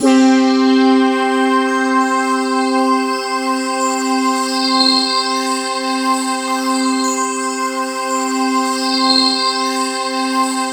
BRASSPADC4-L.wav